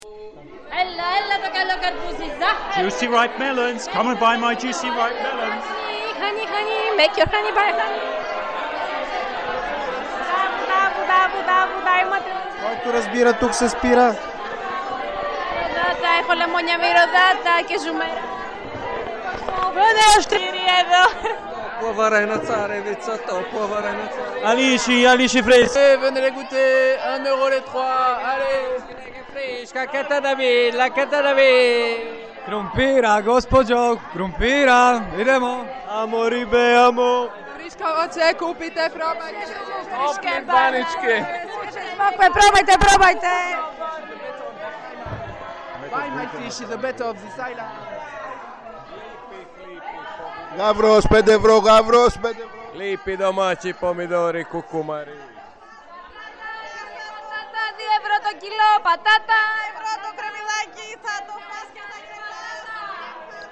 Paesaggio sonoro
open-air-market.mp3